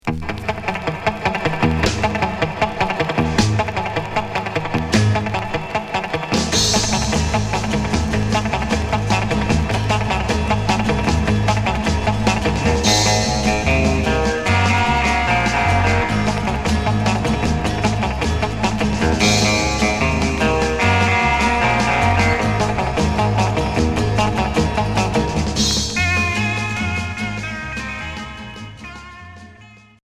Rock instrumental Groupe franco-suisse